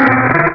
Cri de Bekipan dans Pokémon Rubis et Saphir.